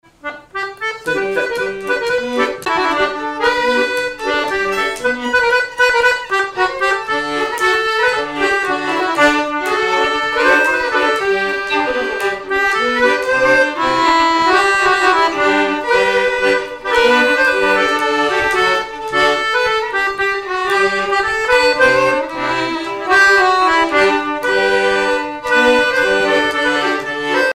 Miquelon-Langlade
danse : marche
violon
Pièce musicale inédite